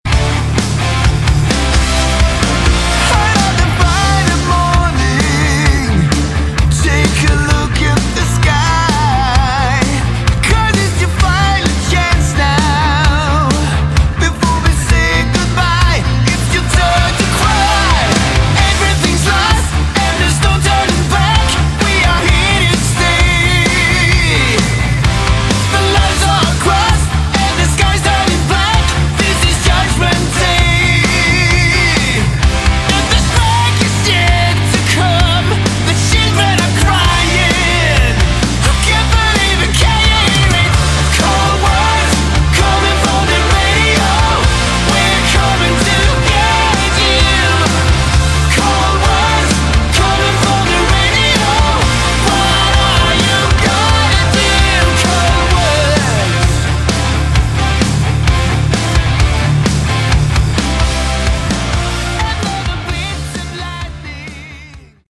Category: Hard Rock
lead vocals
guitars, vocals
bass, vocals
drums, vocals